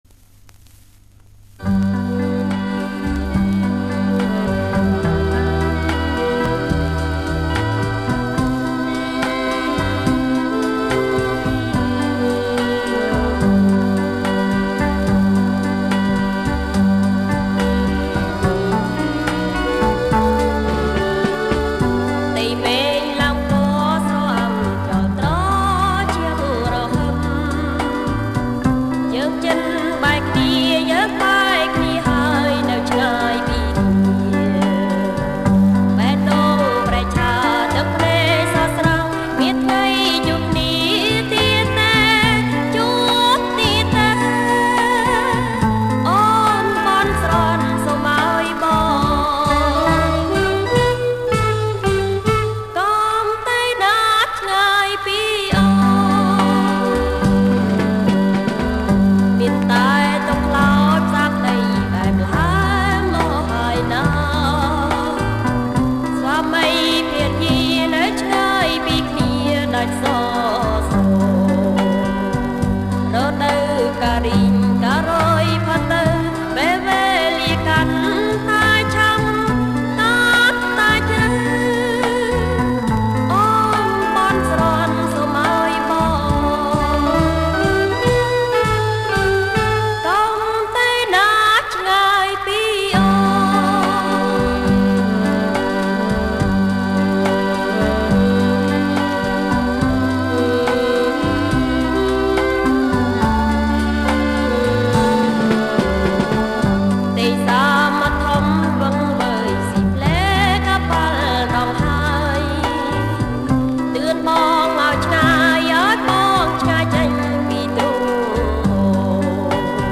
• ចង្វាក់ Slow Rock